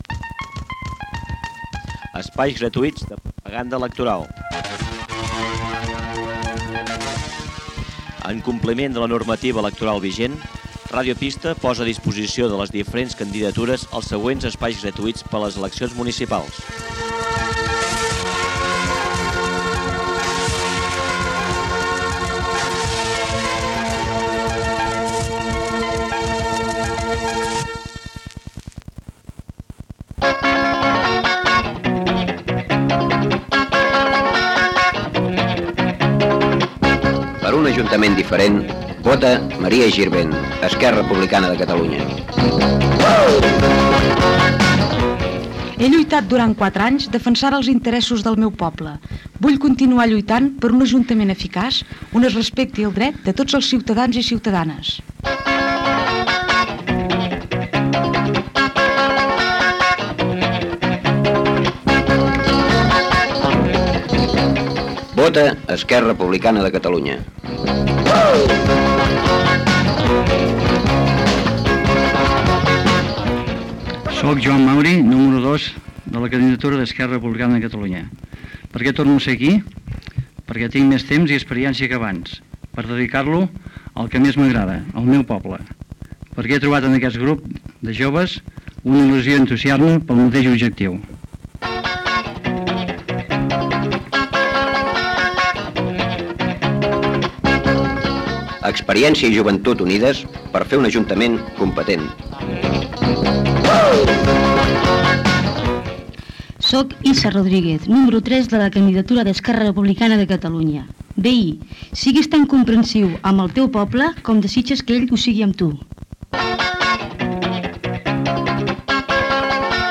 Eleccions municipals. Careta del propaganda, propaganda d'Esquerra Republicana de Catalunya, indicatiu del programa, propaganda de Convergència i Unió, careta del programa